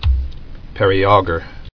[pe·ri·au·ger]